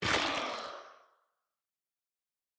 Minecraft Version Minecraft Version latest Latest Release | Latest Snapshot latest / assets / minecraft / sounds / block / sculk_shrieker / break4.ogg Compare With Compare With Latest Release | Latest Snapshot